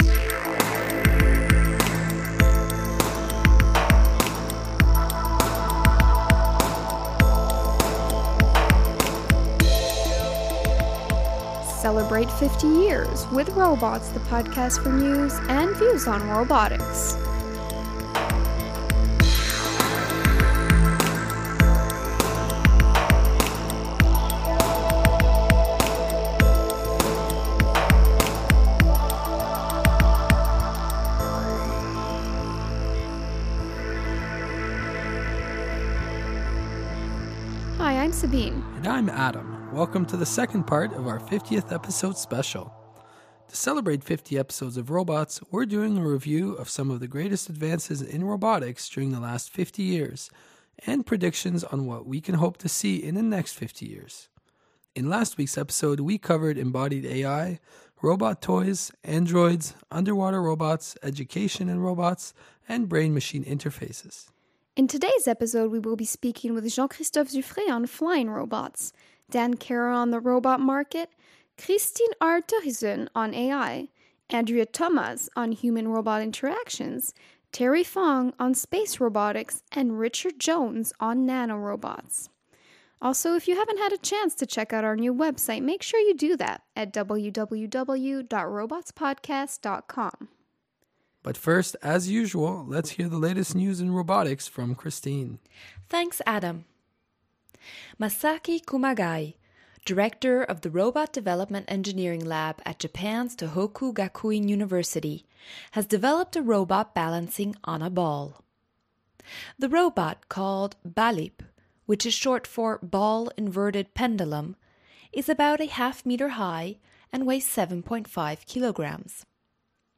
Robots Podcast overview of the interviews Listen to the interview (Direct link to MP3 file)